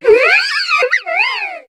Cri de Lockpin dans Pokémon HOME.